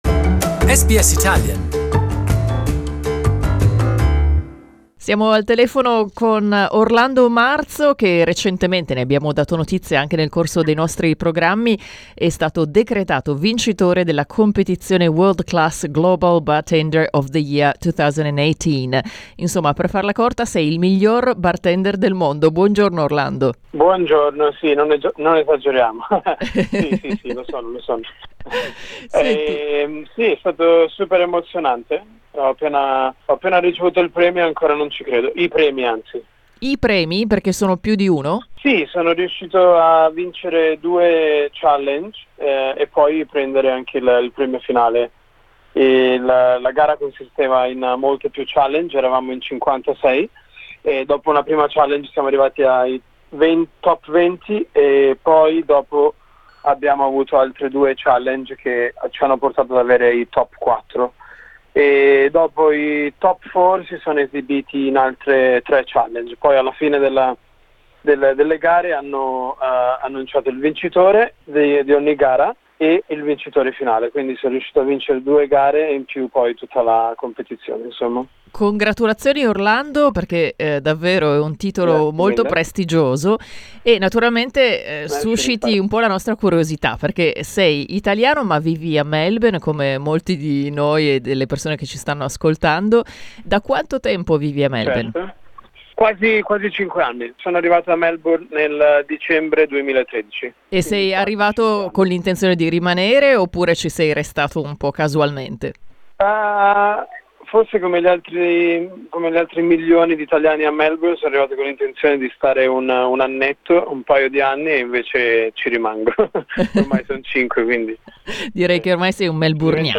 We reached him over the phone to find out more about his profession and his background.